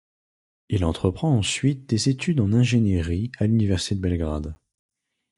Read more engineering Frequency C2 Pronounced as (IPA) /ɛ̃.ʒe.ni.ʁi/ Etymology From ingénieur (“engineer”) + -erie.